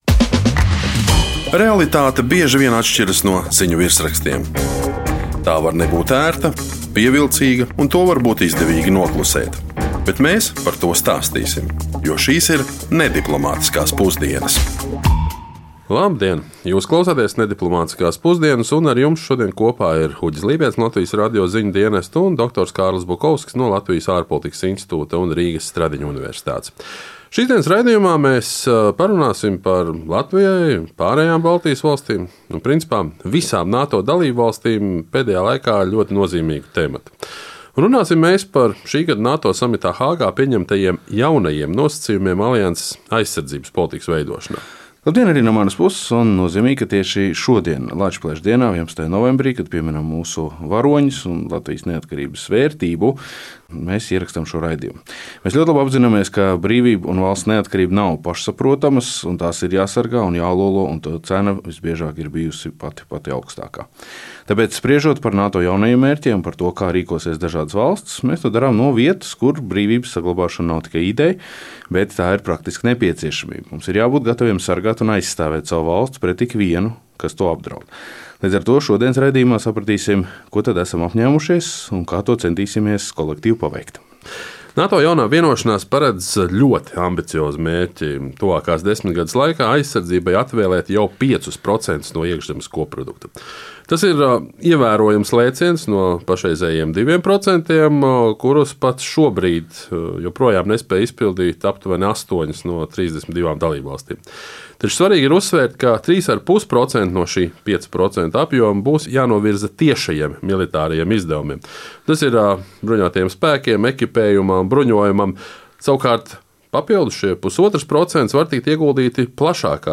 Raidījumā (ne)Diplomātiskās pusdienas saruna par Latvijai, pārējām Baltijas valstīm un principā visām NATO dalībvalstīm nozīmīgu tematu. Tie ir šī gada NATO samitā Hāgā pieņemtie jaunie nosacījumi alianses aizsardzības politikas veidošanā.